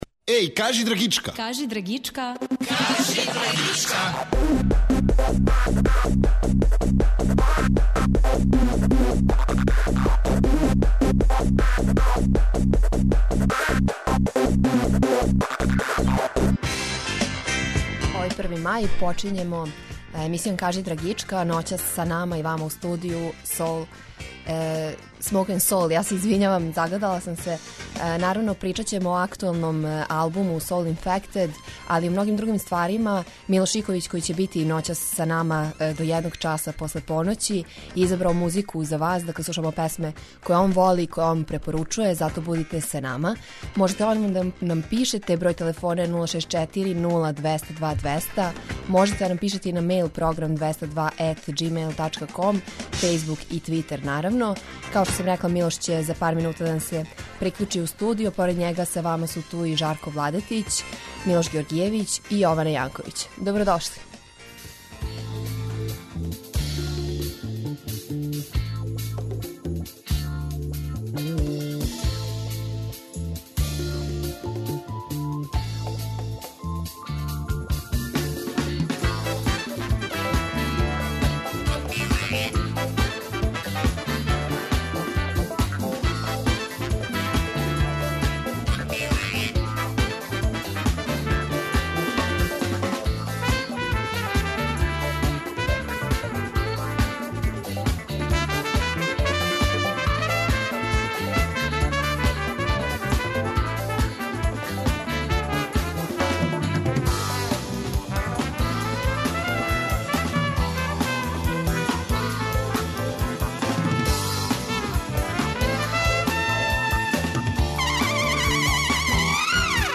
Ноћас од поноћи у студију Smoke'n'Soul.